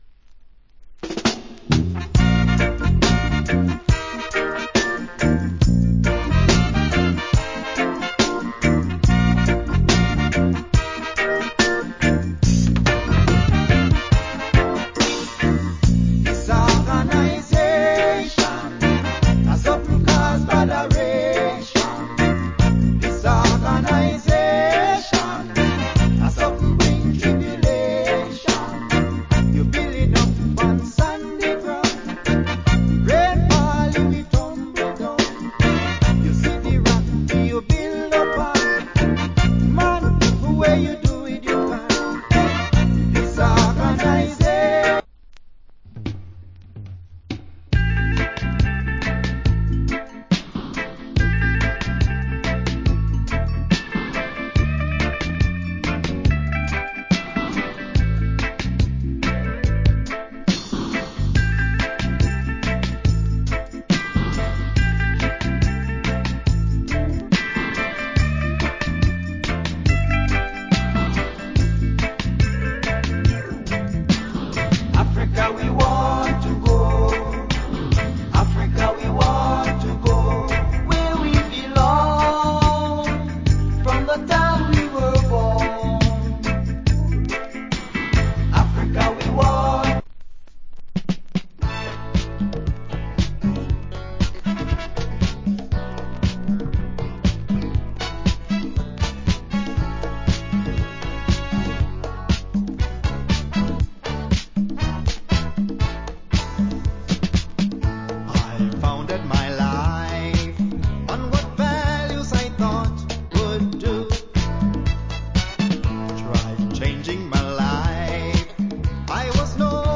REGGAE ROOTS ROCK
Early 80's Roots Rock & Reggae Best Album.
80's